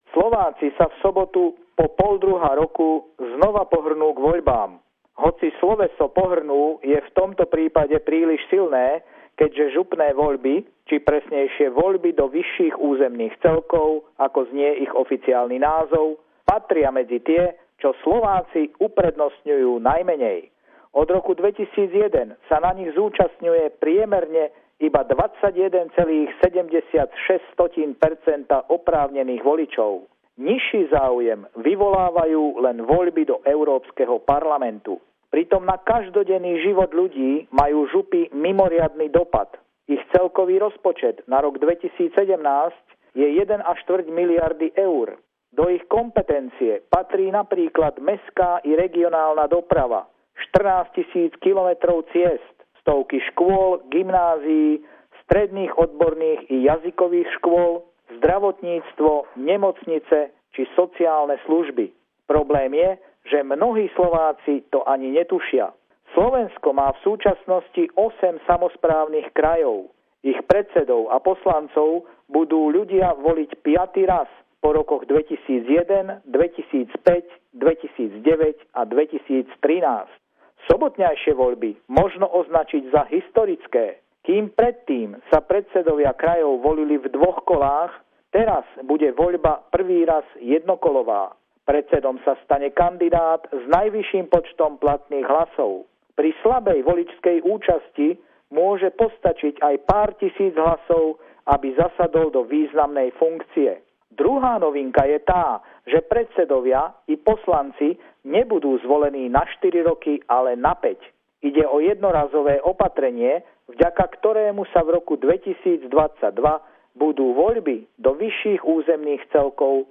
Pravidelný telefonát týždňa